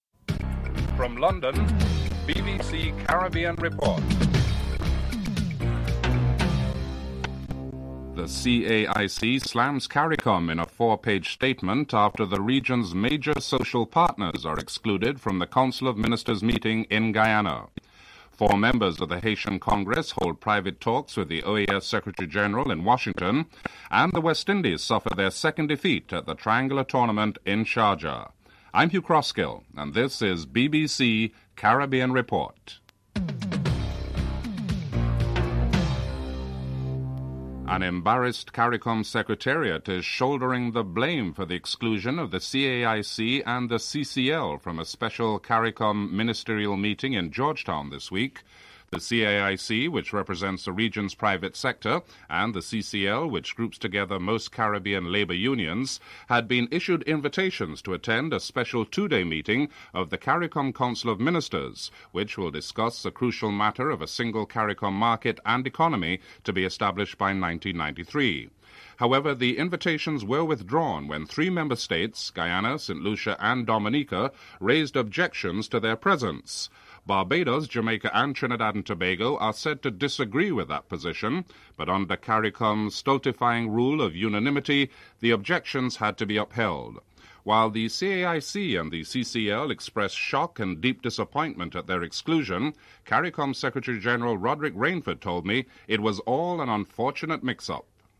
Distorted audio at the beginning of the report.